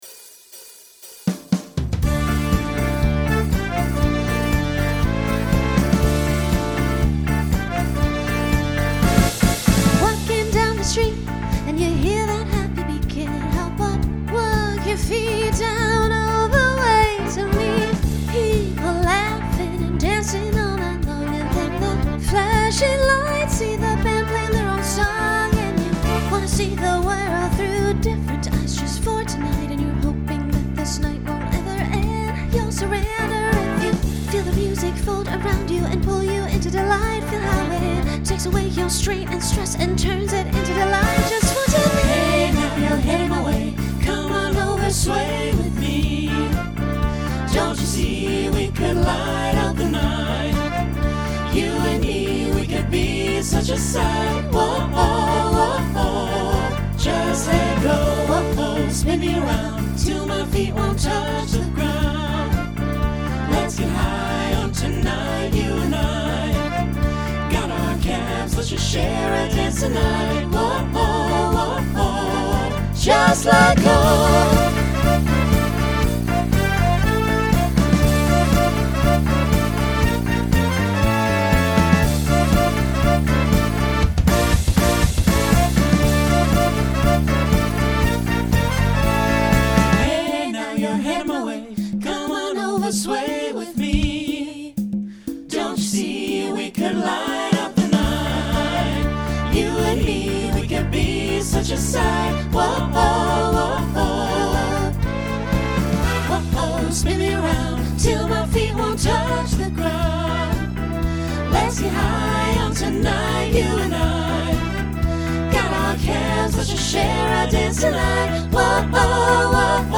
Genre Rock , Swing/Jazz Instrumental combo
Voicing SATB